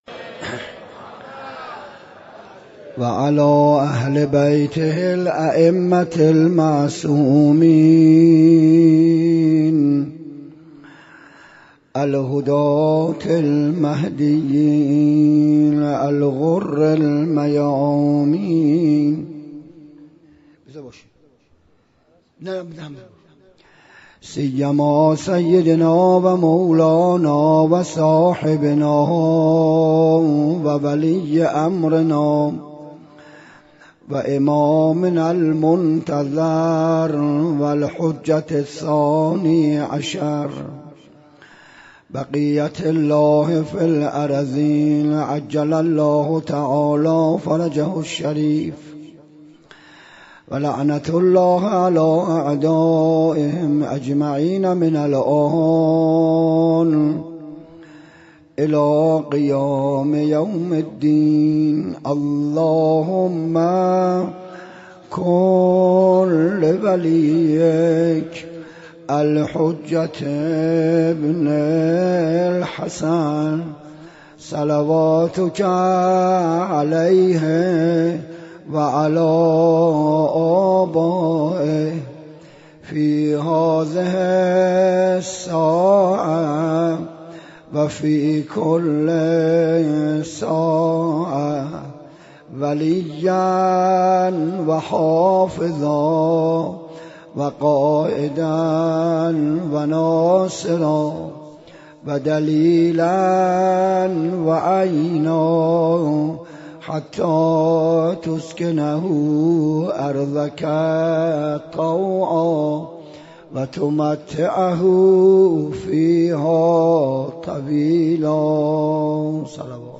وفات حضرت ابوطالب (ع) 96 - مسجد حضرت موسی ابن جعفر - سخنرانی